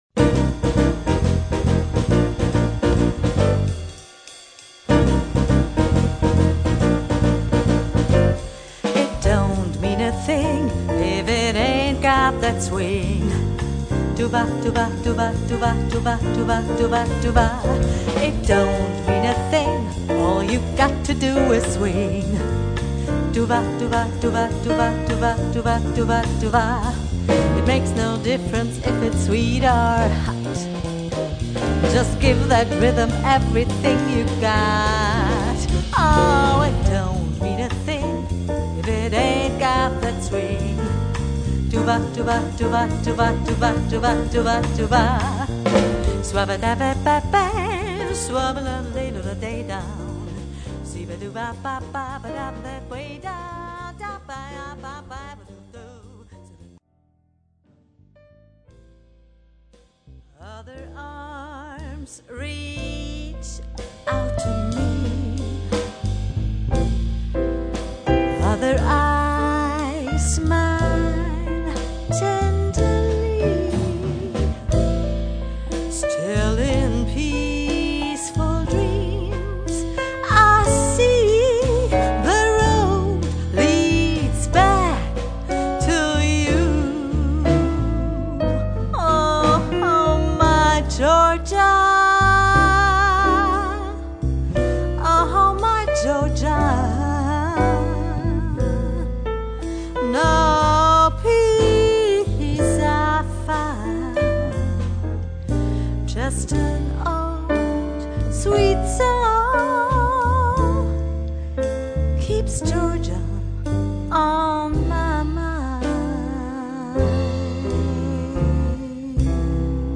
vocals
piano
drums
Bass ein kurzer Höreindruck